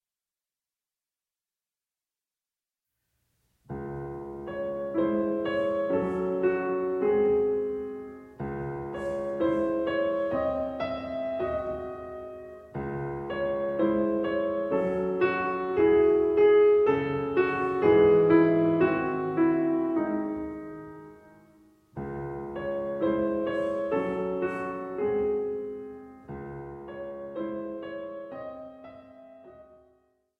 Piano Music